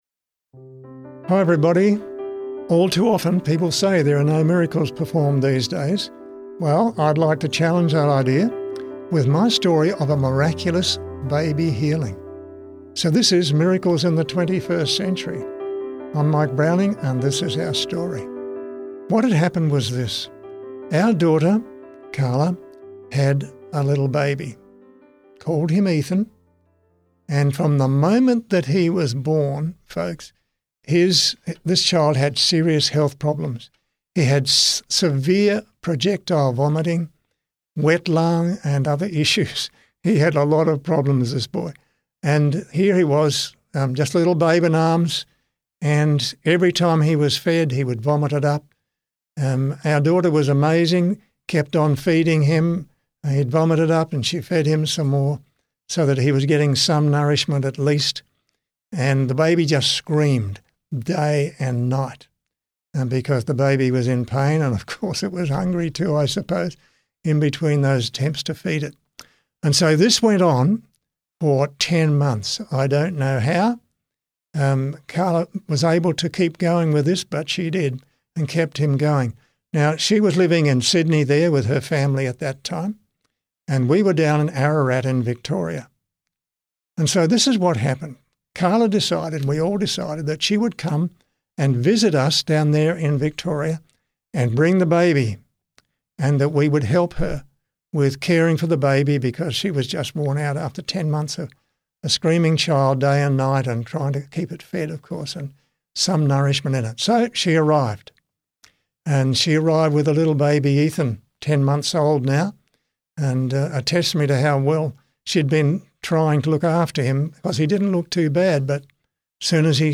• Miraculous baby healing testimony